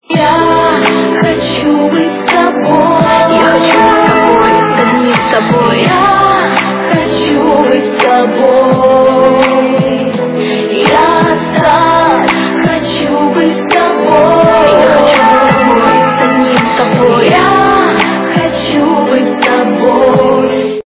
- русская эстрада
качество понижено и присутствуют гудки.